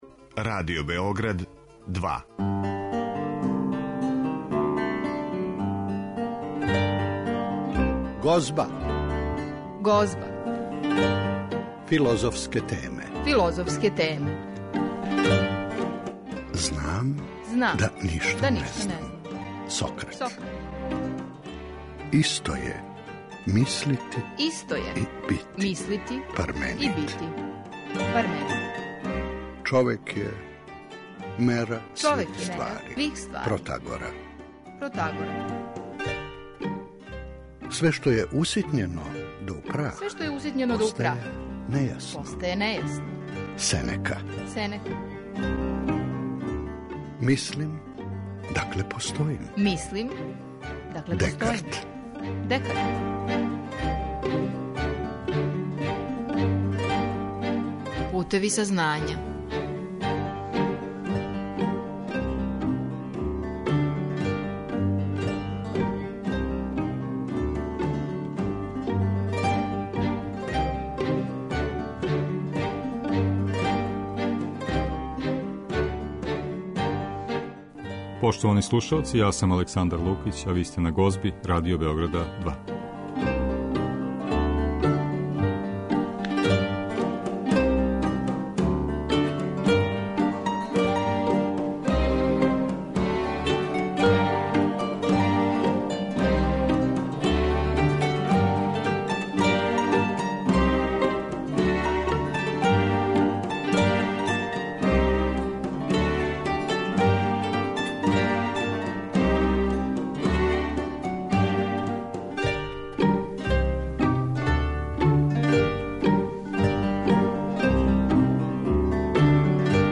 Емитоваћемо делове излагања са недавно завршене Крушевачке филозофско-књижевне школе.